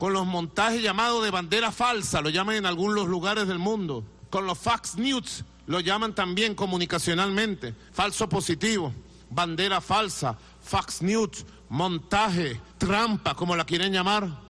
Nicolás Maduro habla sobre las banderas falsas y las fake news